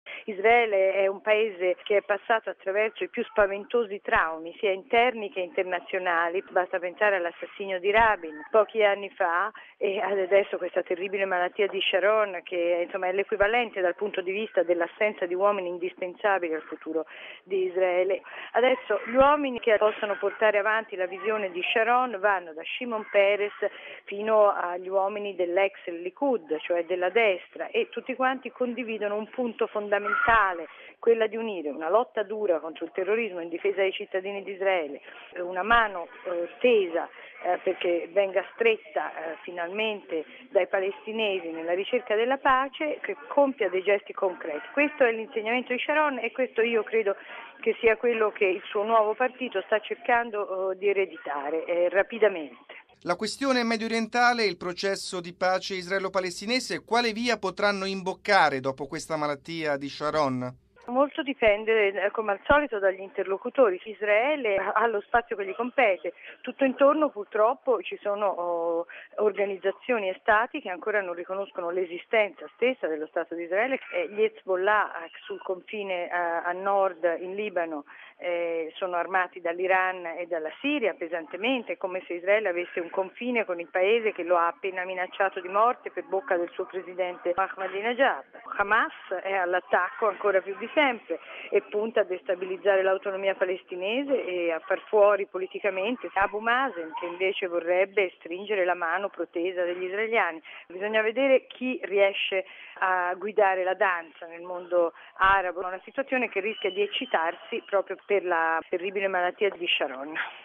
E’ di questo parere anche l’editorialista del quotidiano “La Stampa”, Fiamma Nirenstein, intervistata